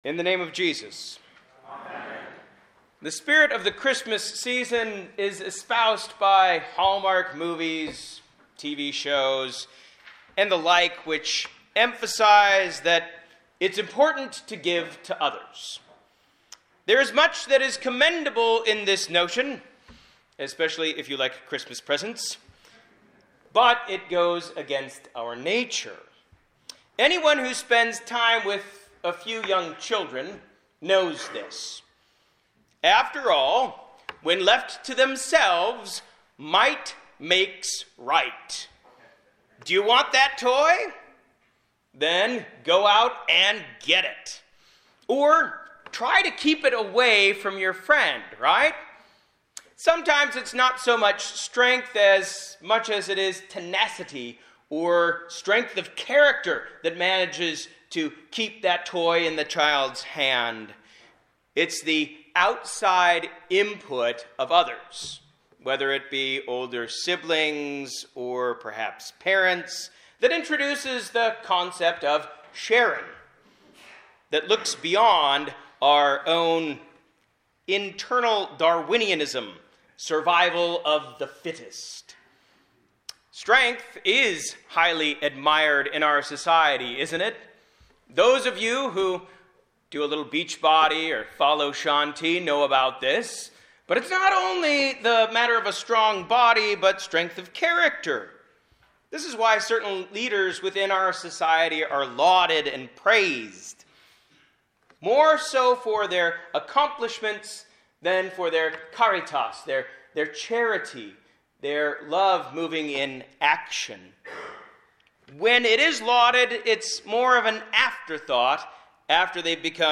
Christmas Eve Divine Service – Luke 2:1-20 – December 24, 2018, 11pm